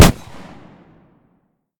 pistol-shot-04.ogg